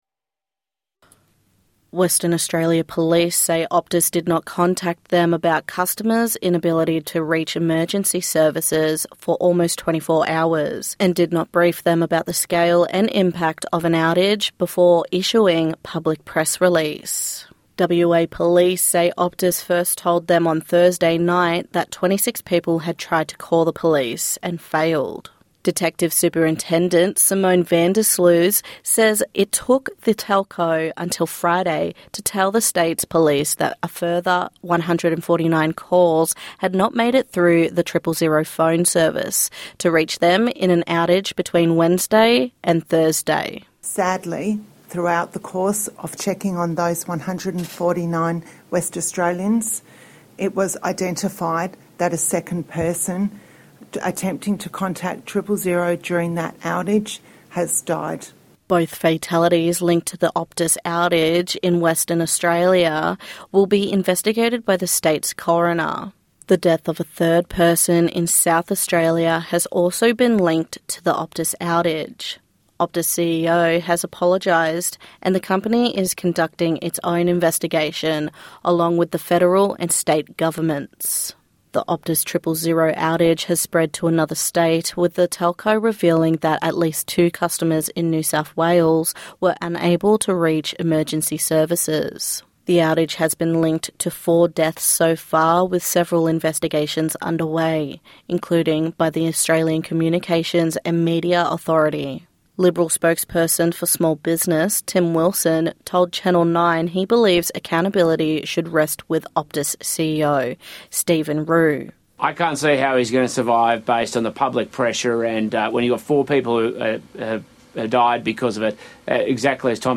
NITV Radio News - 22/09/1995